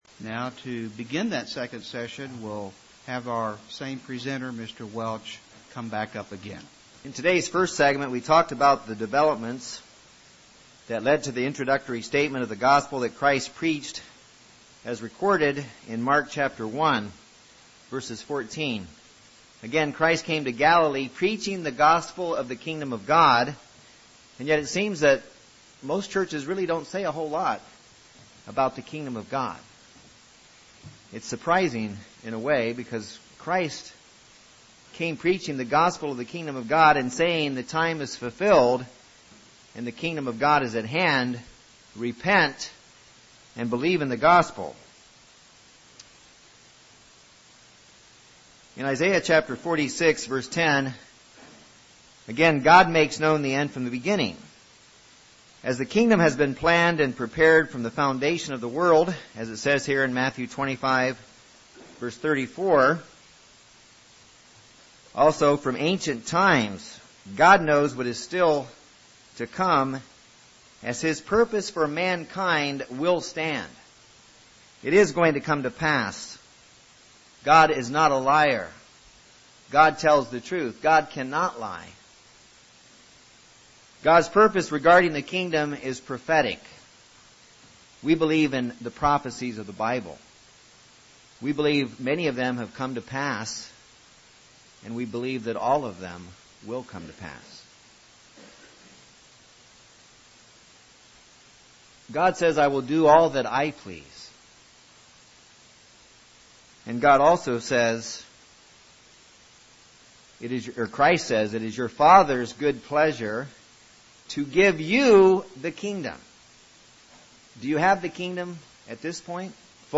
Kingdom of God Bible Seminar Series, Part 2, Session 2 Just what is the Gospel of the Kingdom of God? Christ included praying “Thy Kingdom come” in the sample prayer for His disciples, but so many do not understand the truth about the Good News of the Kingdom of God.